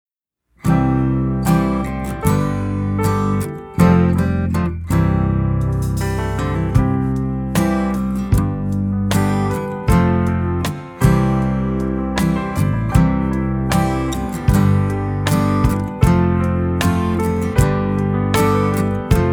Listen to a sample of this instrumental song.